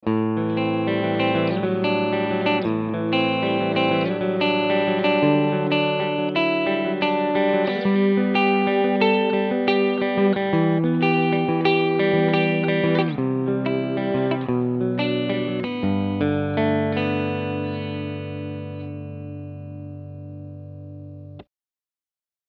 Die Roswell LAF 5 Humbucker liefern in Kombination mit dem Mahagoni Holz sehr schöne Sounds.
Harley Benton EX-76 Classic GHW AN Soundbeispiele
Ich habe für alle Beispiele meinen Mesa Boogie Mark V 25 mit dem CabClone D.I. verwendet.